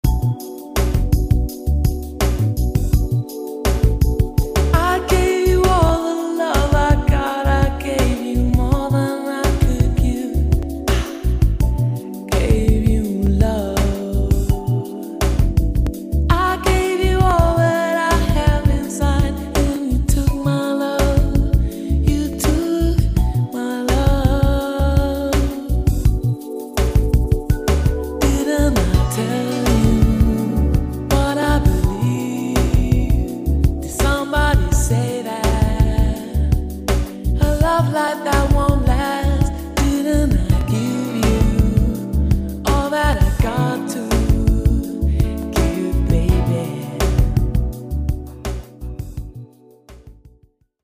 Una voce splendida, morbida e suadente come il suo viso.